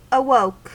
Ääntäminen
Synonyymit awaked Ääntäminen : IPA : /əˈwəʊk/ US : IPA : [əˈwəʊk] Haettu sana löytyi näillä lähdekielillä: englanti Käännöksiä ei löytynyt valitulle kohdekielelle.